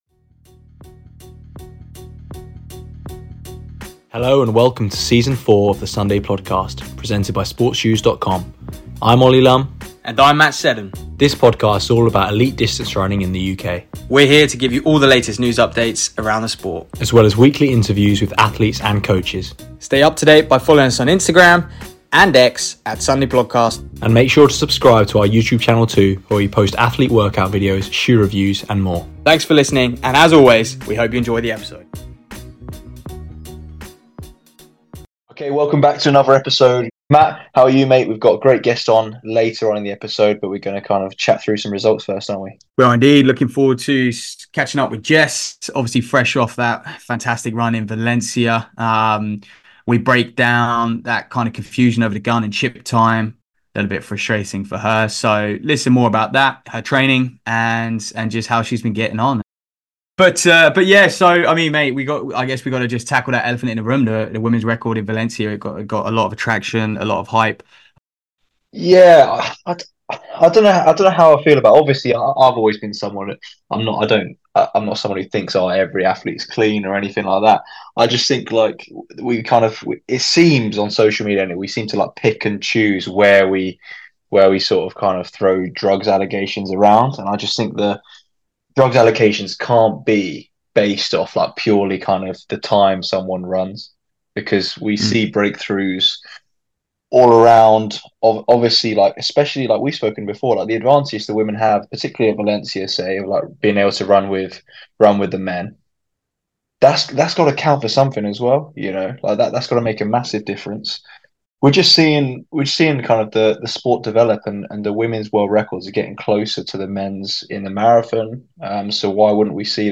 Then, following her 30:41 performance in the same race, Jess Warner-Judd was welcomed back to the podcast for this week's interview. Jess spoke about some of her training going into Valencia, and much more.